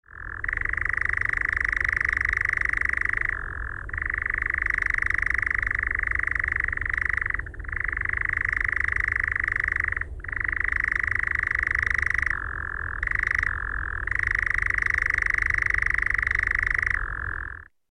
nachtzwaluw1.mp3